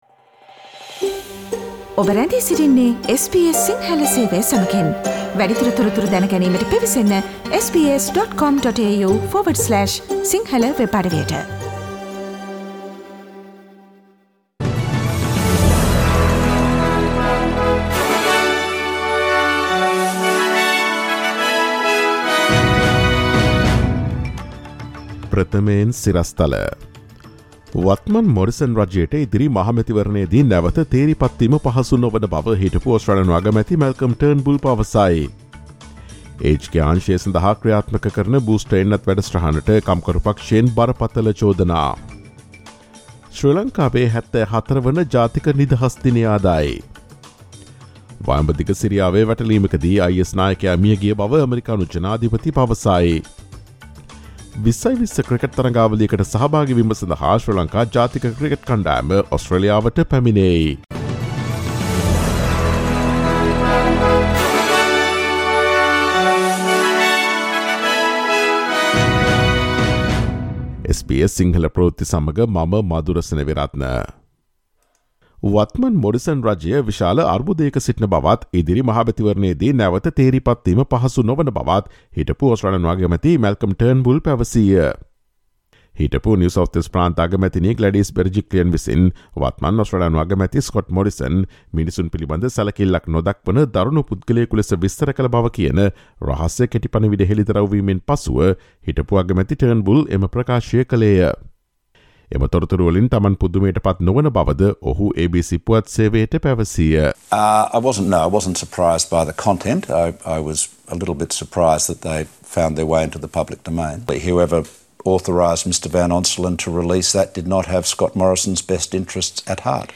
ඔස්ට්‍රේලියාවේ සහ ශ්‍රී ලංකාවේ නවතම පුවත් මෙන්ම විදෙස් පුවත් සහ ක්‍රීඩා පුවත් රැගත් SBS සිංහල සේවයේ 2022 පෙබරවාරි 04 වන දා සිකුරාදා වැඩසටහනේ ප්‍රවෘත්ති ප්‍රකාශයට සවන් දීමට ඉහත ඡායාරූපය මත ඇති speaker සලකුණ මත click කරන්න.